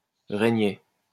Regney (French pronunciation: [ʁəɲɛ]